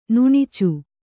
♪ nuṇicu